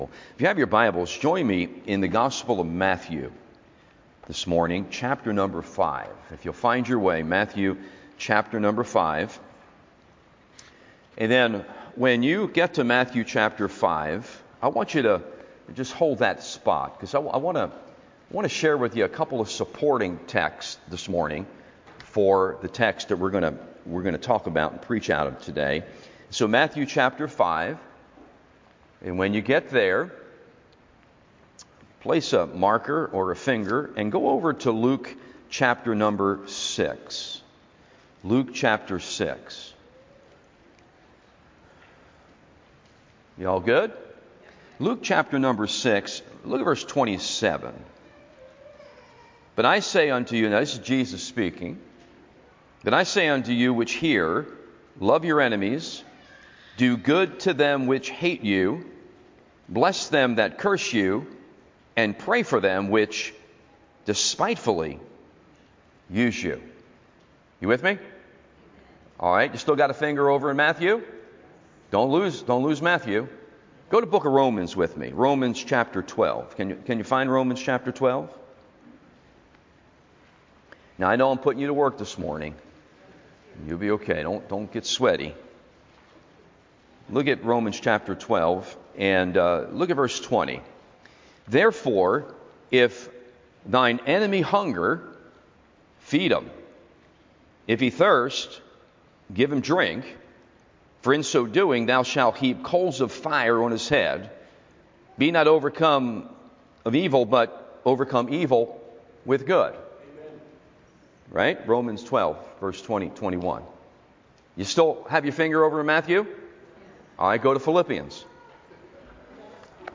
The Family of God Sunday AM Service